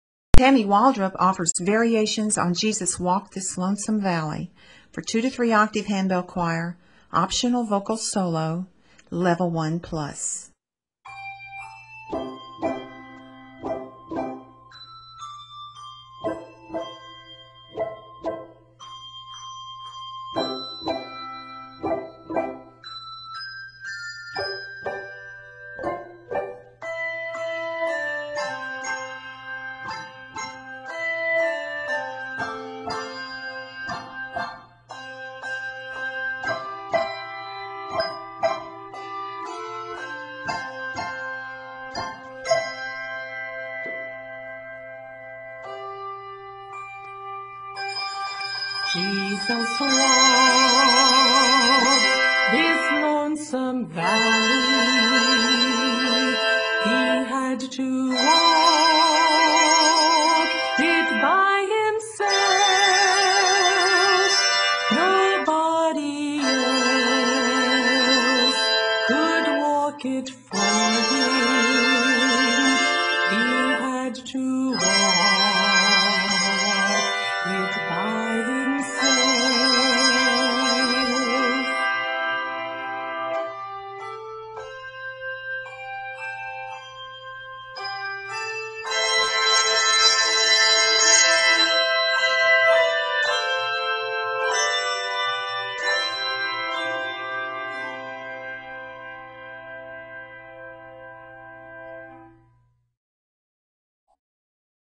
for 2-3 octave handbell choir with an optional vocal solo